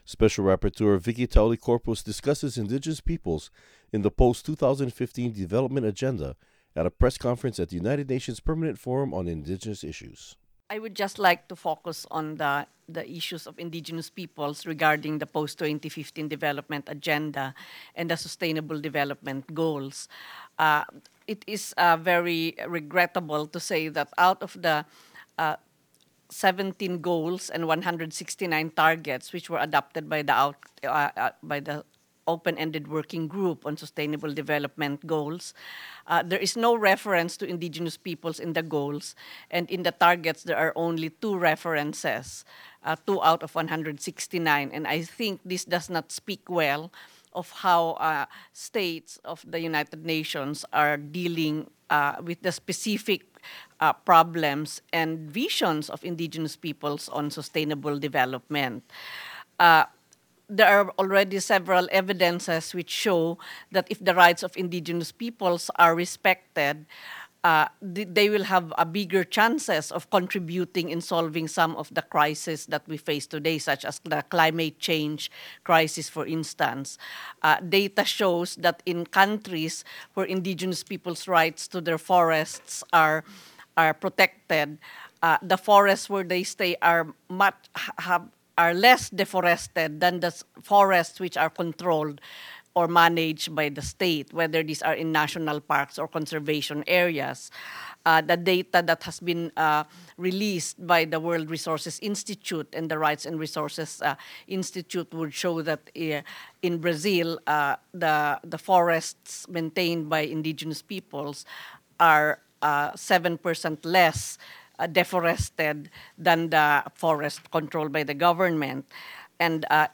Recording Location: UNPFII 2015
Description: Statement from Special Rapporteur Vicky Talui-Corpuz on the sustainable development goals proposed by the United Nations and how Indigenous Peoples' rights must be respected in order to solve climate issues such as deforestation.
Type: Interview
UNPFII_Vicky_Press_Conference.mp3